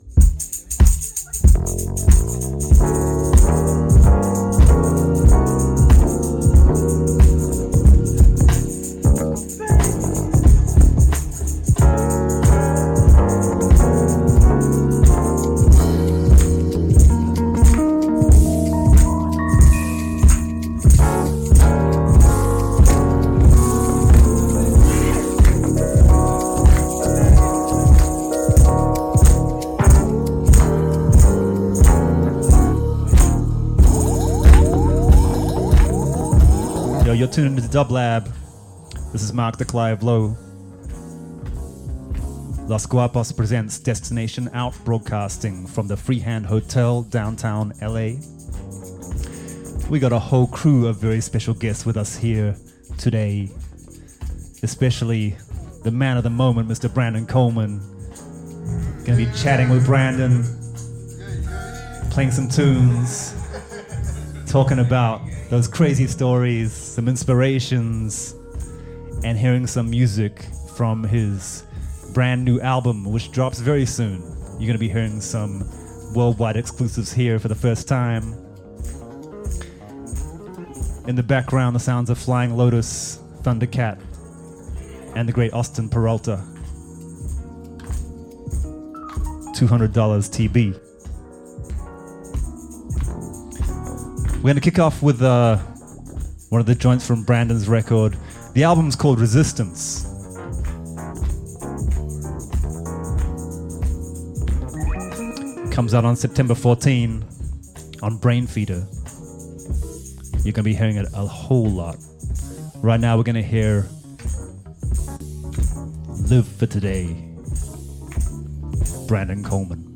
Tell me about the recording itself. The Destination OUT! Radio Hour is presented by Los Guapos and recorded live at the Freehand Hotel in Downtown LA.